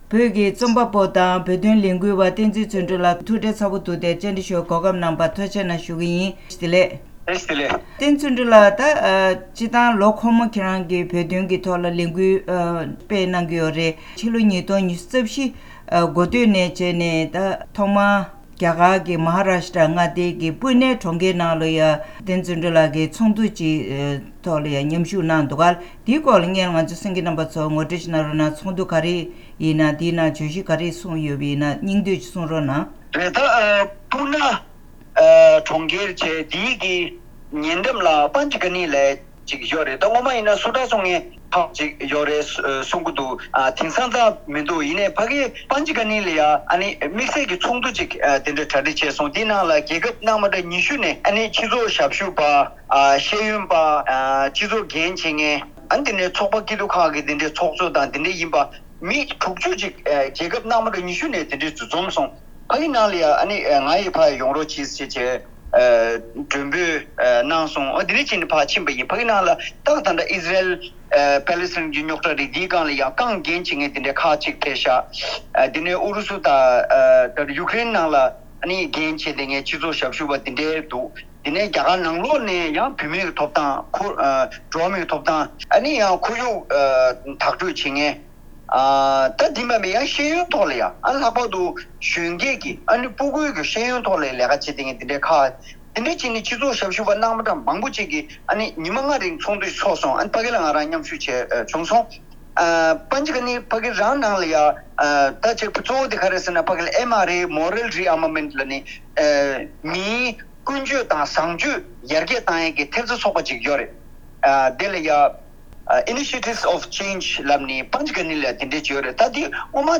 གནས་འདྲིའི་ལེ་ཚན་ནང་།